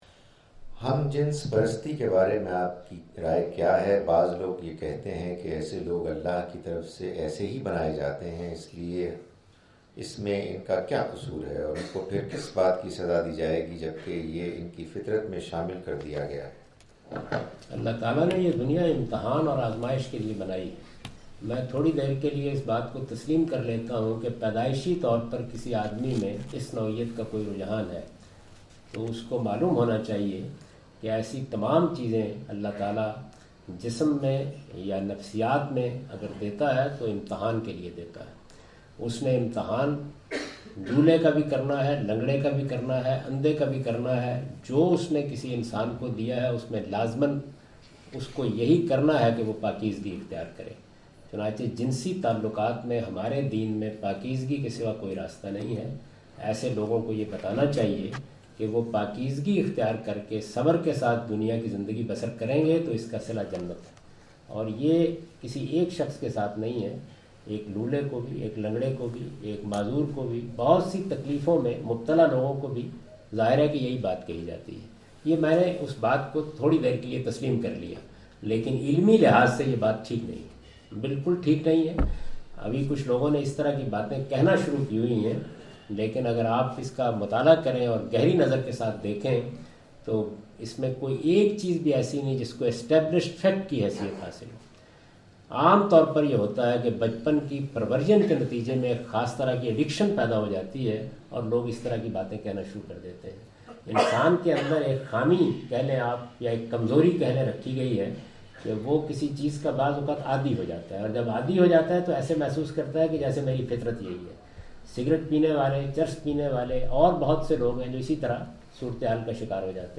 Category: English Subtitled / Questions_Answers /
Javed Ahmad Ghamidi answer the question about "Islamic teachings about homosexuality" during his visit to Manchester UK in March 06, 2016.